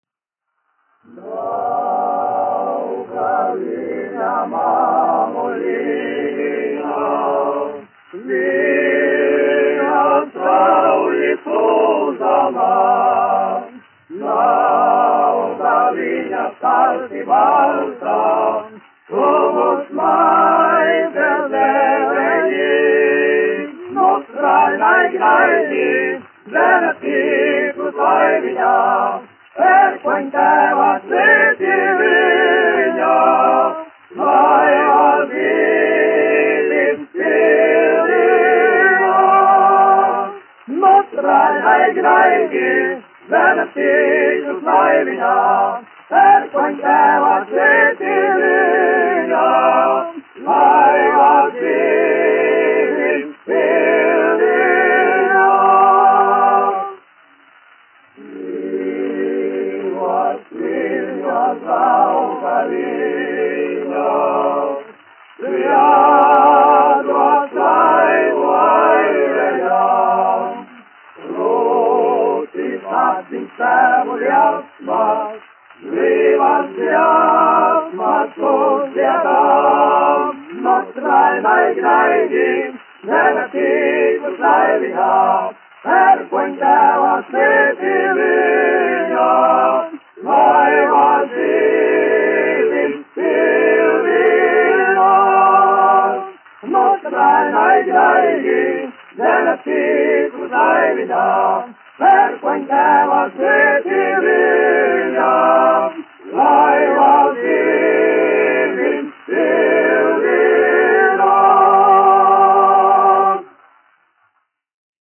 1 skpl. : analogs, 78 apgr/min, mono ; 25 cm
Vokālie seksteti
Skaņuplate